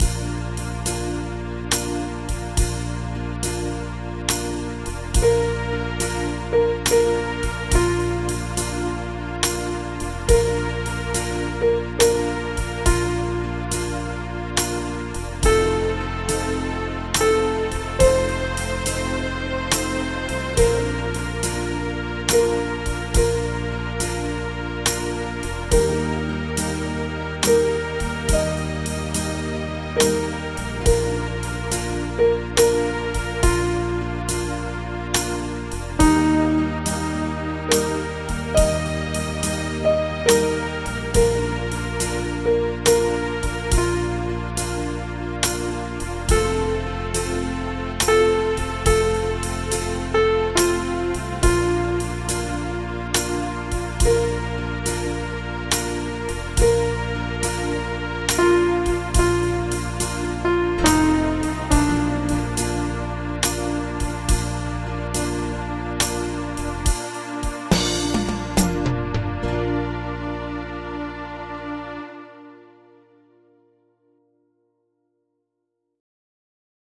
Audio Midi Bè Tenor: download